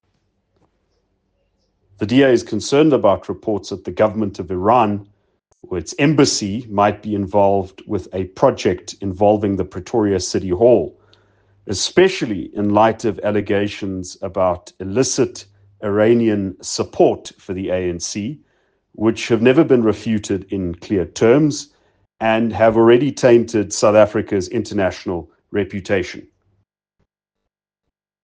Note to Editors: Please find English and Afrikaans soundbites by Ald Cilliers Brink